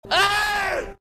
death5.wav